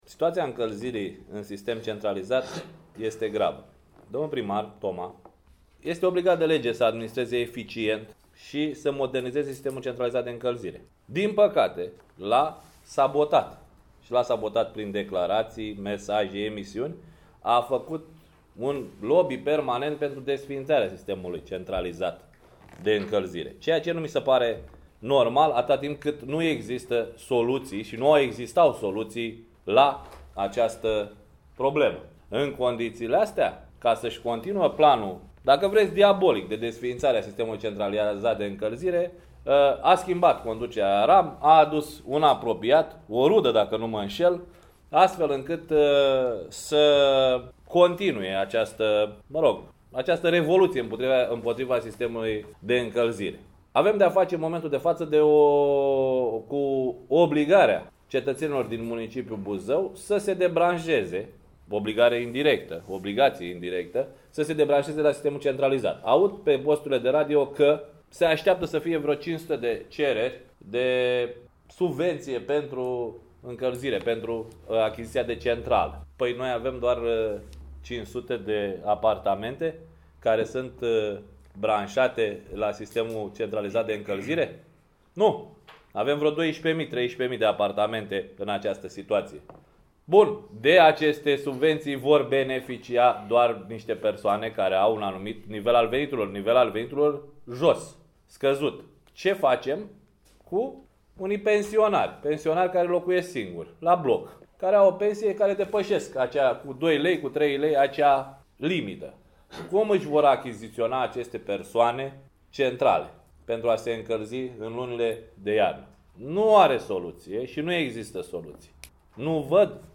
În cursul zilei de joi, la sediul organizației județene a formațiunii politice ALDE Buzău a avut loc o conferință de presă în cadrul căreia s-a discutat printre altele despre societatea Compania de Apă și despre sistemul centralizat de încălzire, mai ales despre proiectul privind montarea centralelor termice în apartamentele familiilor nevoiașe, atunci când RAM fie nu va mai furniza căldură în iarna ce va urma, fie nu va mai funcționa.
Primul care a deschis discuția a fost senatorul Bădulescu ce a ținut să facă unele precizări privind situația sistemului centralizat de încălzire.